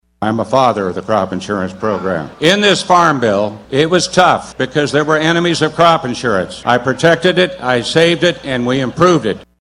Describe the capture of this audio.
The debate was held at the Kansas State Fair in Hutch.